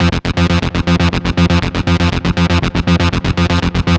TSNRG2 Lead 001.wav